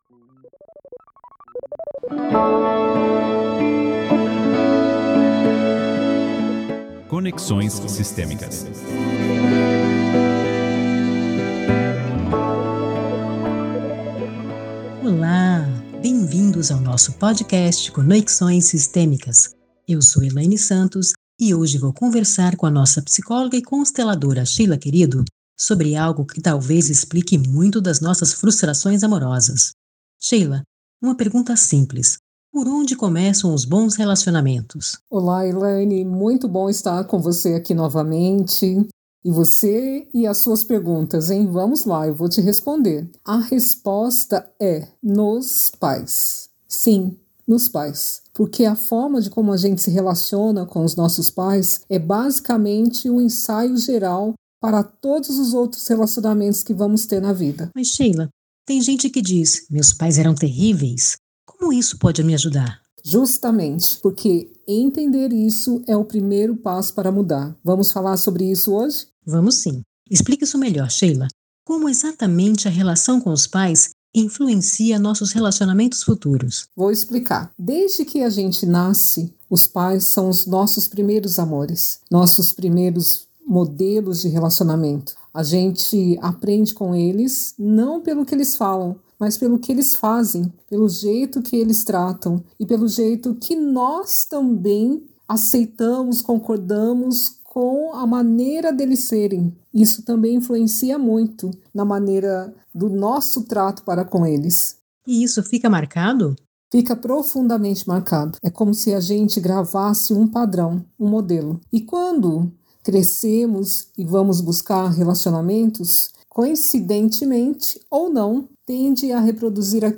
Descubra o primeiro passo para construir relacionamentos saudáveis e duradouros. Uma conversa para você se entender profundamente.